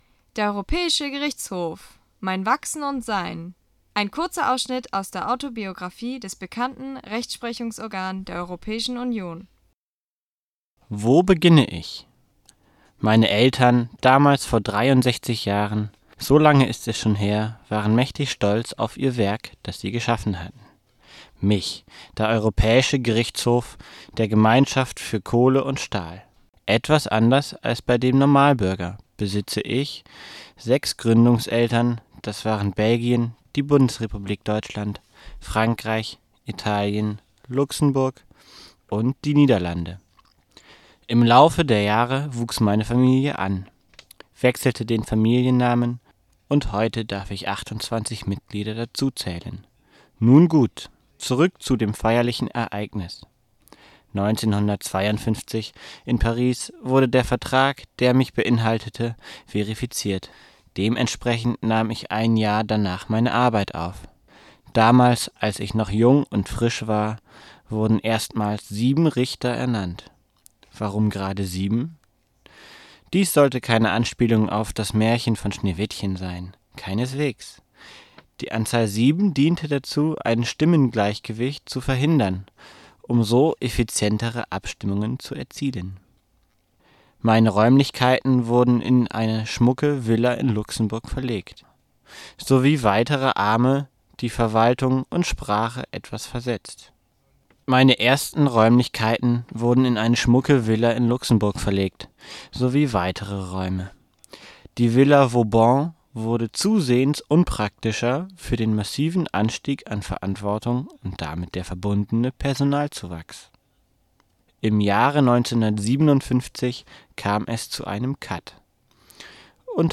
Wir freuen uns immens zu verkünden, dass er trotz seiner regen Geschäftigkeit, einen selbsverlesenen Kurzausschnitt aus seiner Autobiographie "Mein Wachsen und Sein" für uns persönlich aufnehmen und veröffentlichen konnte. Denn auch seine Stimmgewalt ist nicht zu unterschätzen und verleiht seinem geschriebenen Stück eine emotionale, tiefgreifende Note.